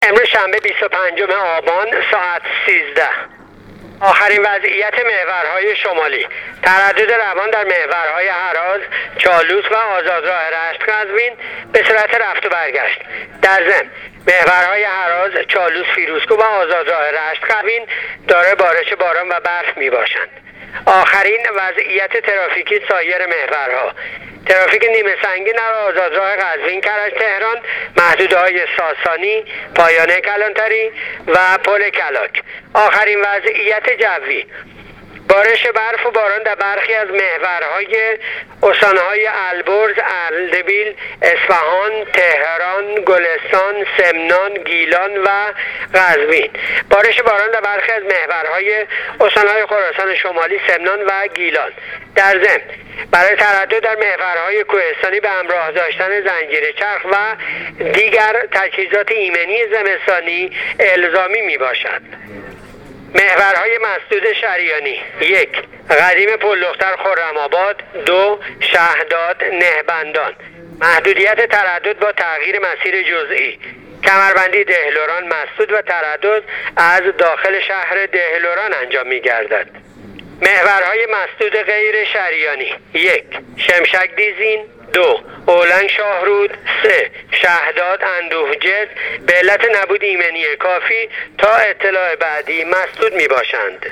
گزارش آخرین وضعیت ترافیکی و جوی جاده‌های کشور را از رادیو اینترنتی پایگاه خبری وزارت راه و شهرسازی بشنوید.